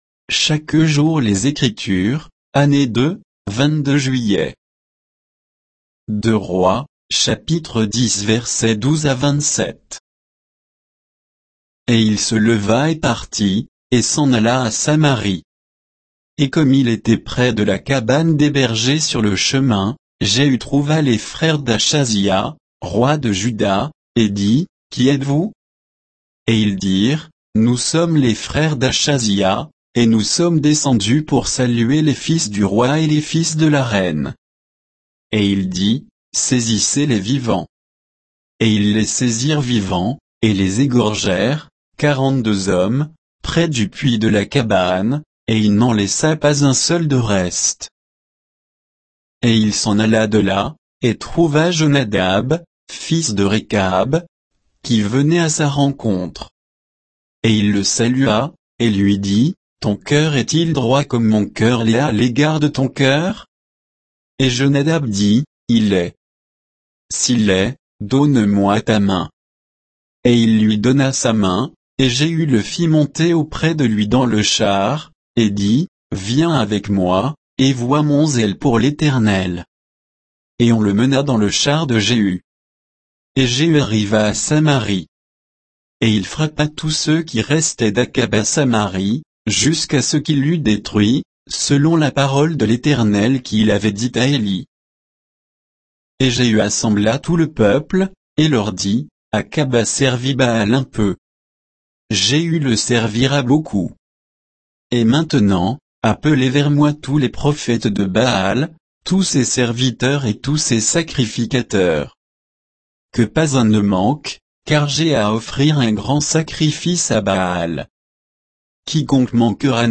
Méditation quoditienne de Chaque jour les Écritures sur 2 Rois 10